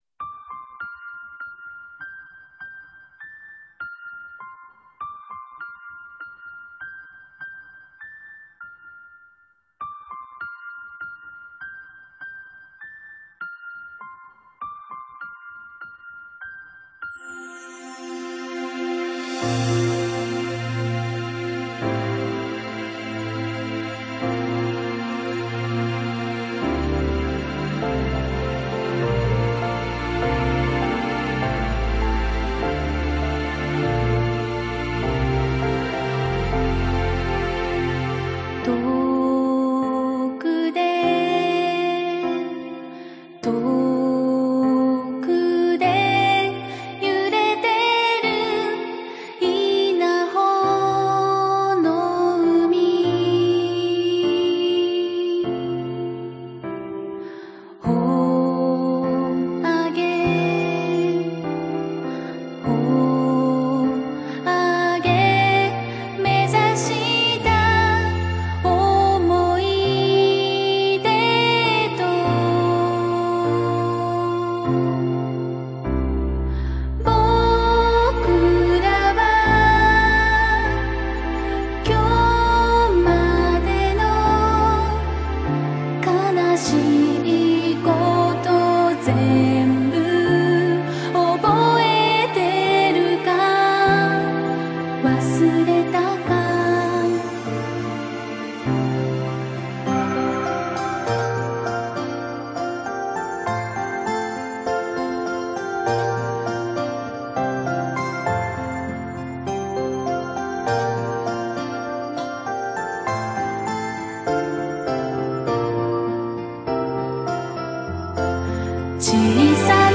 Genre: J-Pop